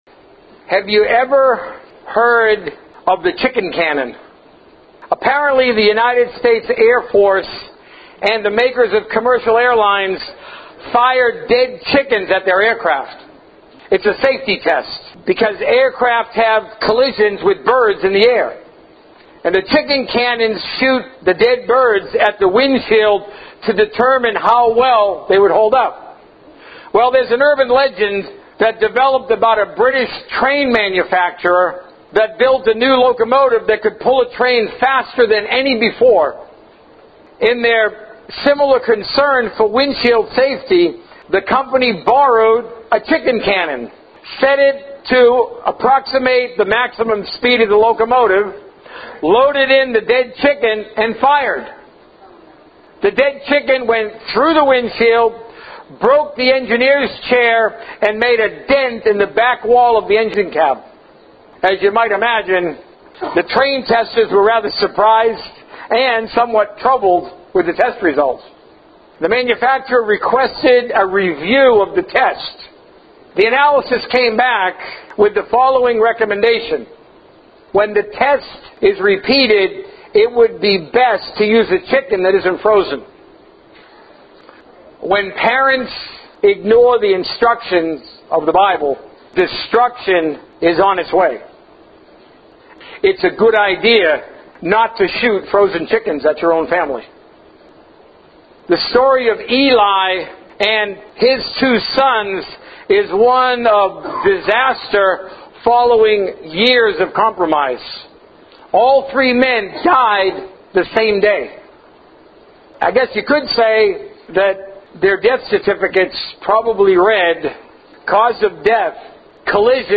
A message from the series "The Source of Success."